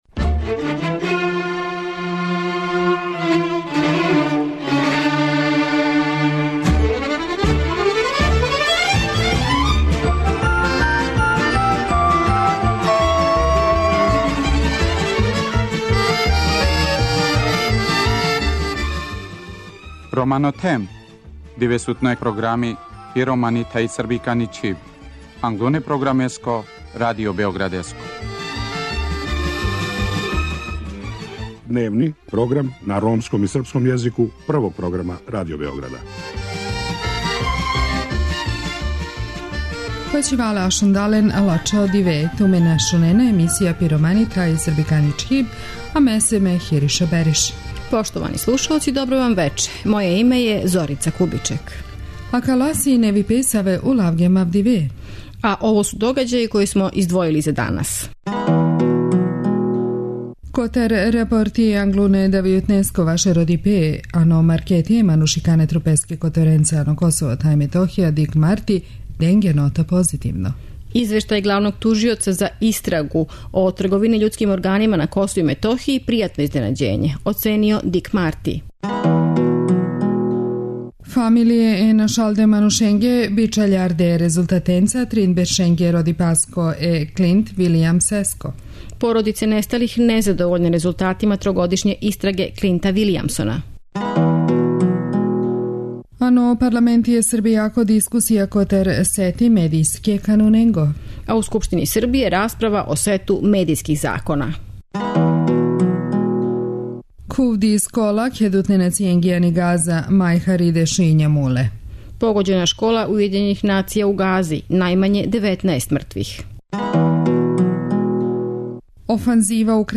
У вечерашњој емисији припремили смо репортажу о девојчицама које су присиљене да пре времена одрасту.